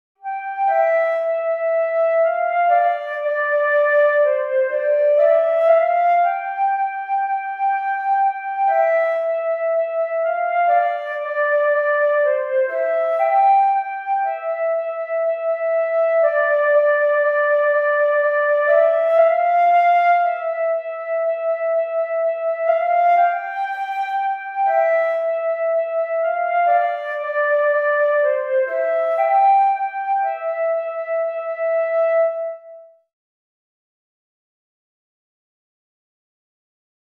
ピアノや楽器など、身体を使って演奏する他に、パソコンを利用して音を出すことができます。